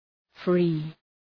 Προφορά
{fri:}